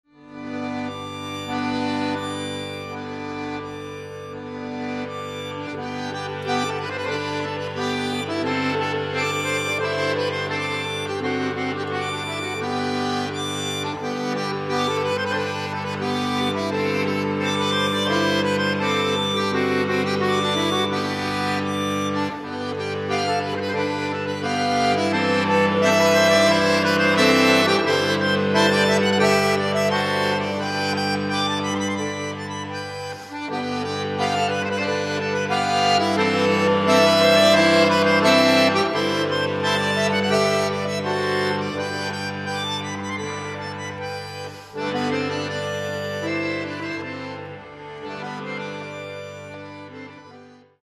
Каталог -> Класична -> Камерна